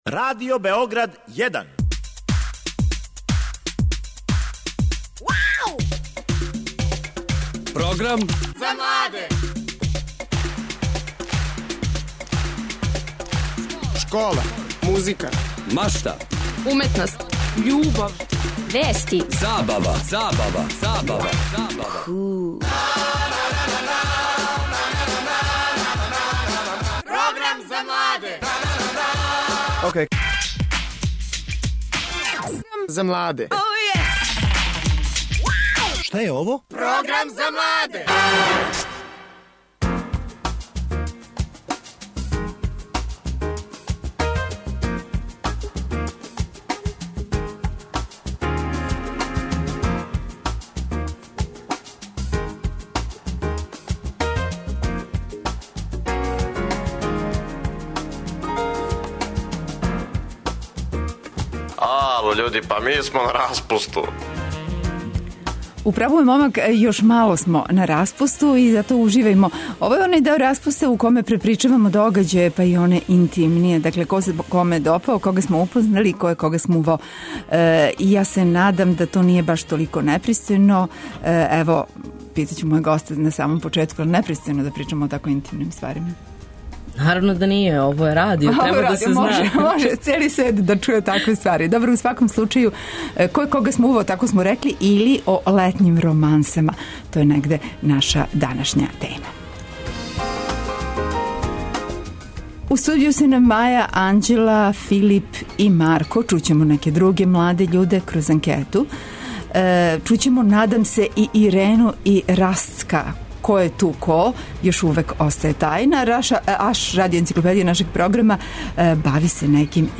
У томе ће нам помоћи наши гости тинејџери и њихови родитељи, а и наша рубрика АШ ће се оврнути на заљубљивање и љубомору.